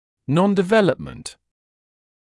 [ˌnɔndɪ’veləpmənt][ˌнонди’вэлэпмэнт]неразвитие